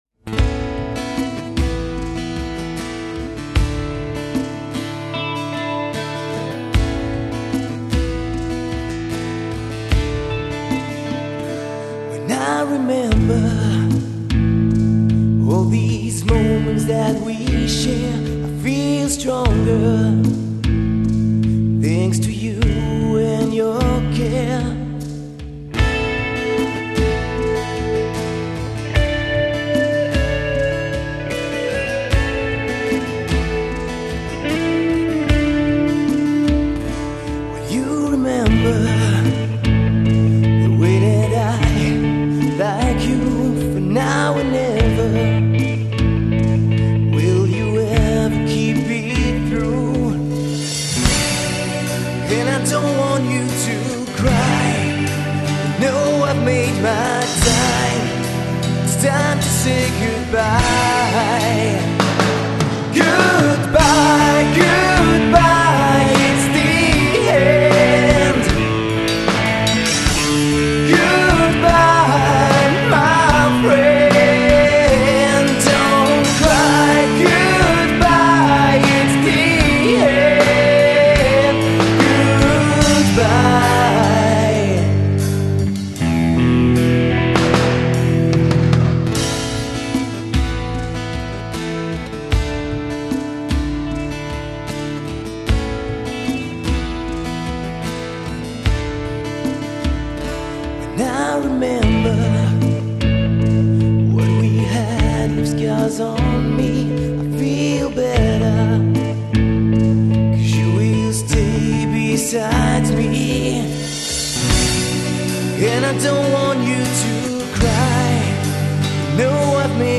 Genre: hard FM
chant
guitare + basse
batterie, clavier, guitare